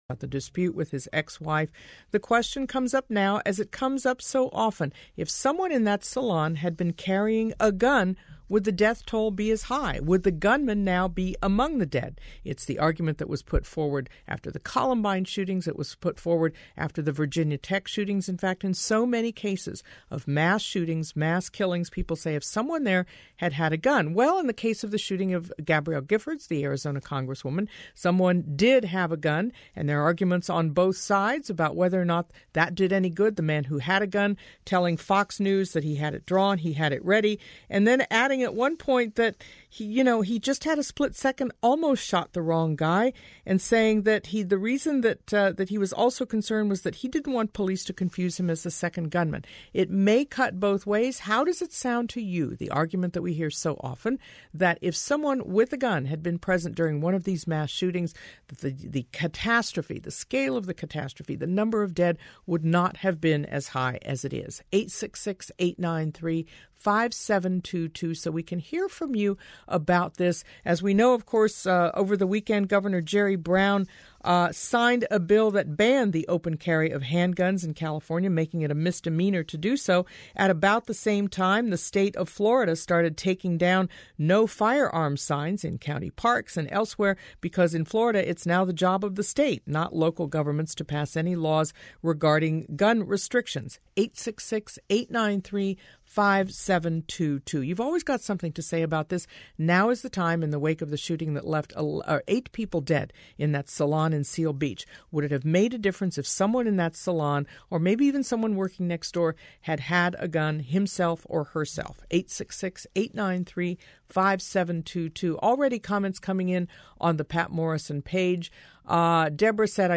John Lott has debated Adam Winkler previously, and they show a pattern of behavior. Most enlightening, here is one example of them debating on KPCC on October 13, 2011.